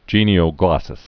(jēnē-ō-glŏsəs)